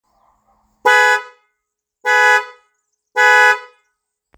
Fanfáry 12V 2-tonový
Klaksony - kvalitní dvoubarevný tón
Houkačka 2 kusy.